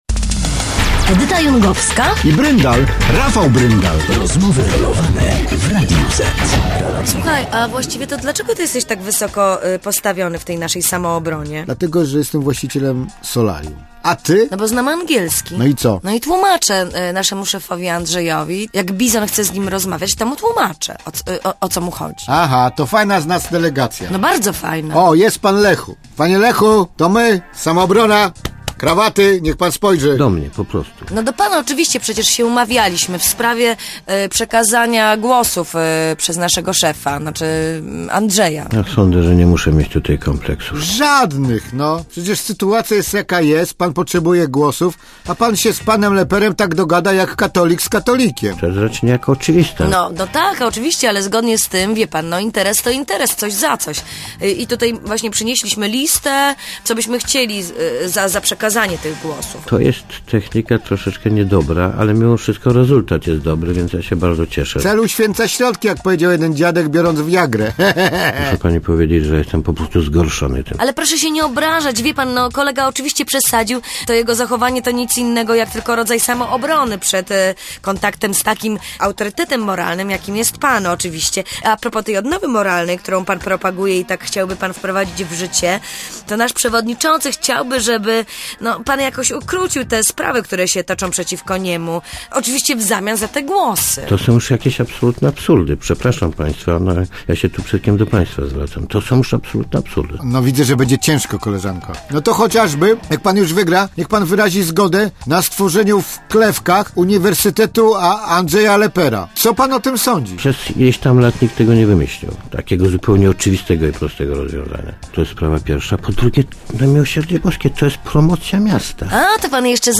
Lech Kaczyński odpowiada na pytania Edyty Jungowskiej i Rafała Bryndala w audycji "Rozmowy Rolowane".